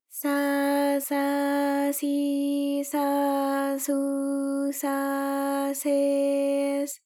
ALYS-DB-001-JPN - First Japanese UTAU vocal library of ALYS.
sa_sa_si_sa_su_sa_se_s.wav